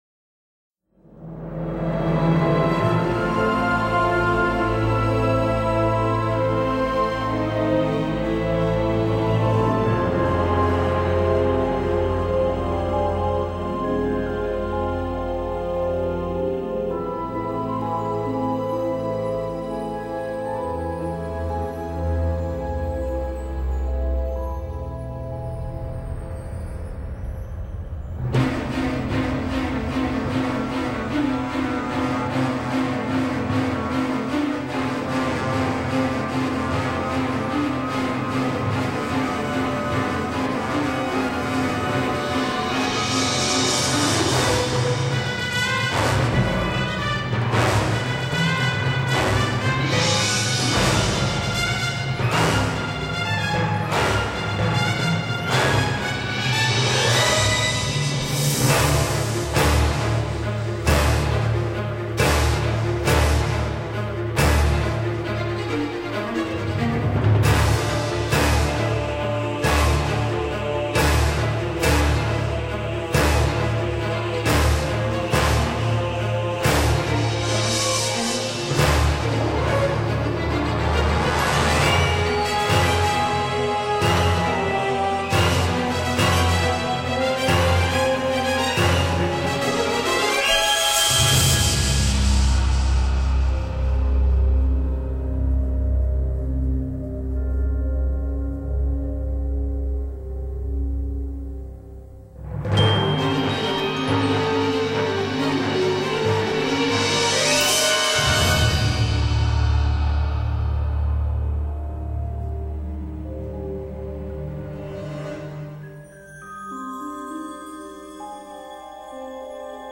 Musique diffusée: Tout au long des scènes avec Dracula.